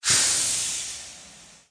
出锅.mp3